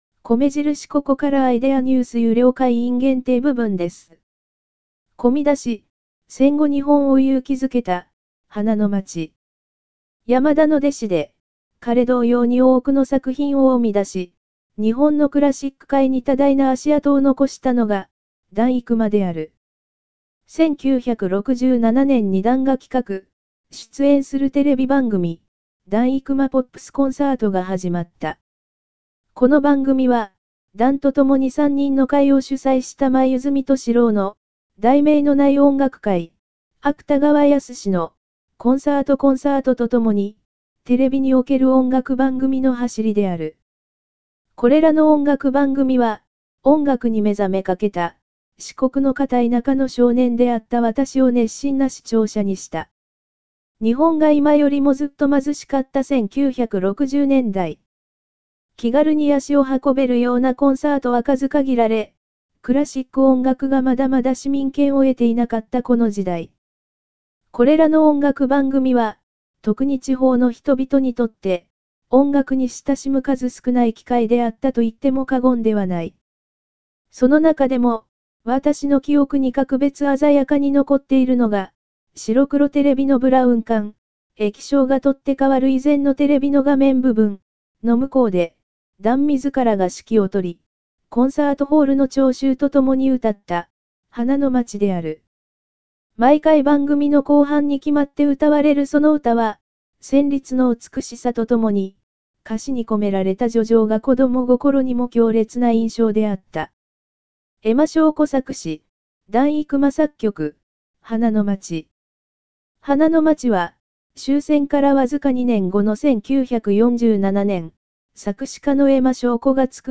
合成音声による読み上げ　⇒一覧